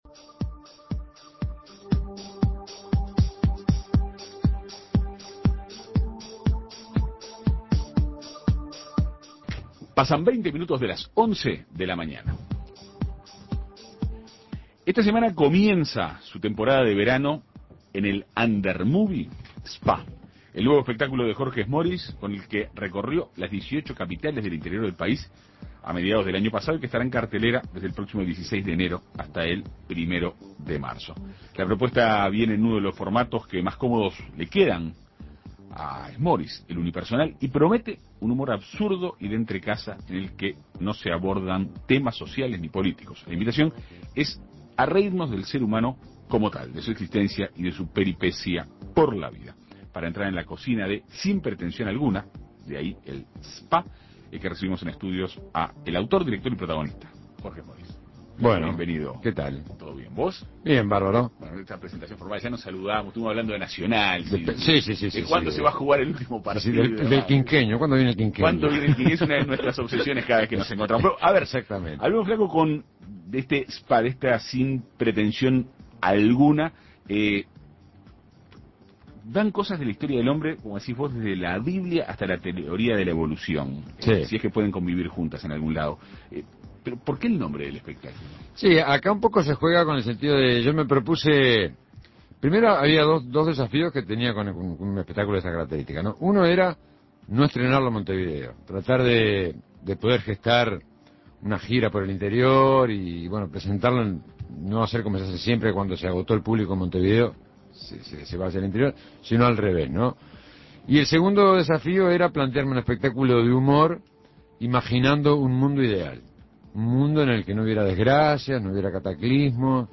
Para conocer a fondo este show, En Perspectiva Segunda Mañana dialogó con su autor, director y protagonista.